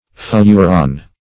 Search Result for " fleuron" : The Collaborative International Dictionary of English v.0.48: Fleuron \Fleu`ron"\, n. [F., fr. OF. floron.
fleuron.mp3